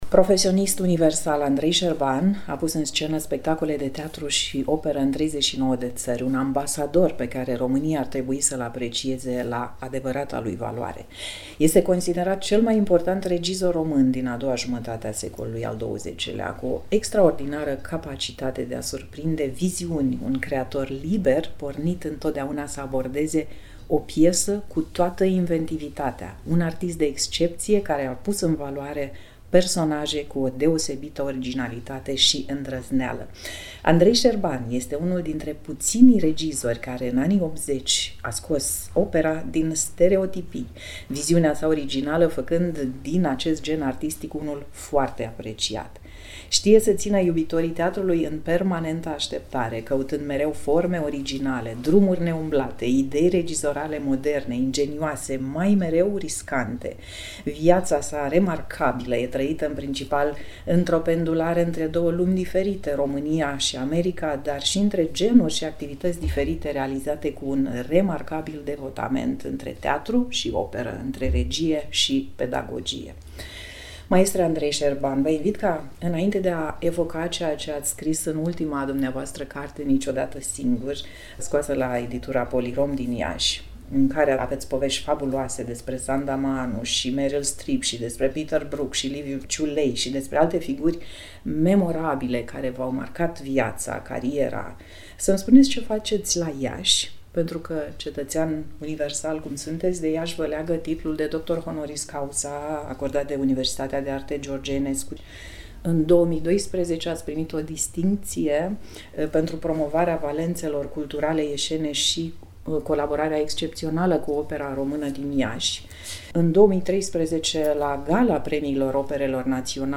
Un interviu
INT.-ANDREI-SERBAN.mp3